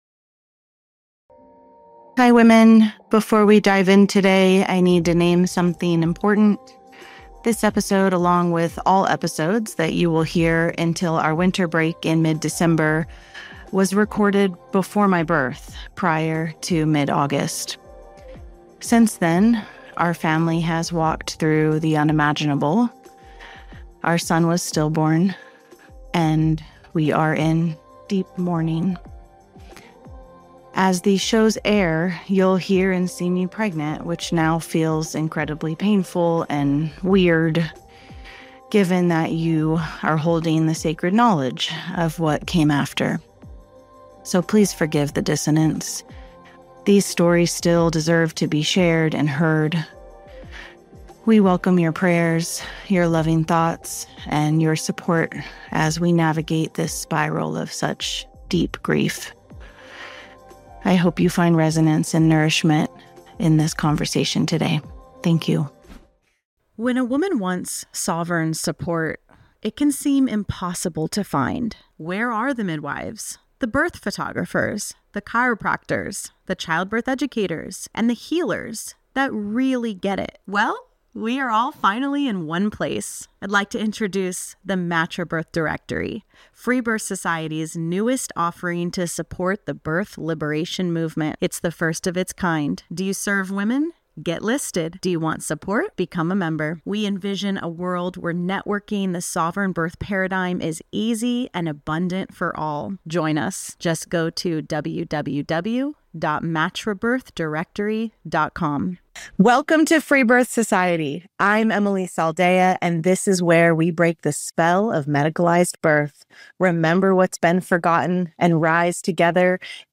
This is a conversation about creativity, courage, and the heroine’s adventure of living boldly, building fearlessly, and standing firmly in who you are.